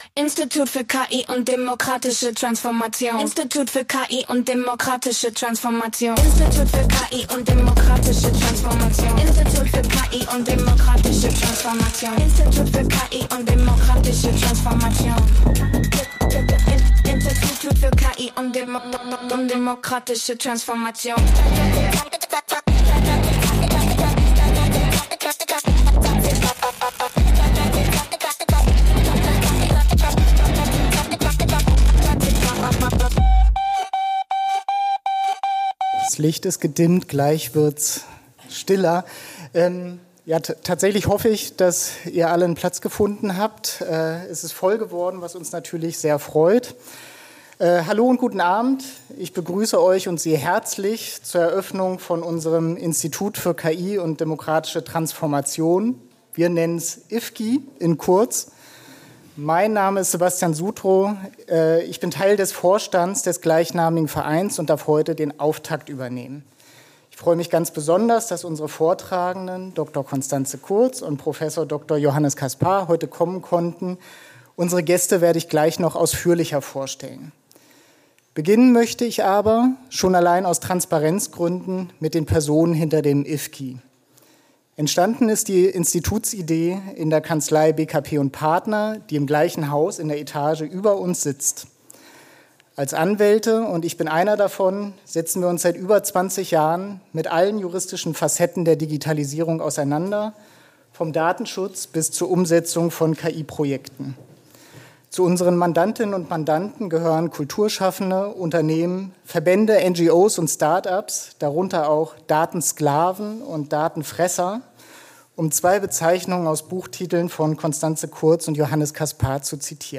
Mitschnitt der IfKI-Eröffnungsveranstaltung vom 09.10.2025 ~ IfKI - Institut für KI und demokratische Transformation Podcast